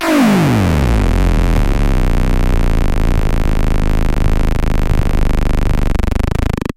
电池玩具猕猴桃" 踢龙
描述：从一个简单的电池玩具中录制的，是用一个猕猴桃代替的音调电阻！
Tag: 音乐学院-incongrue 电路弯曲